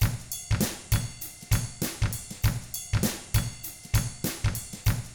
99FUNKY4T4-L.wav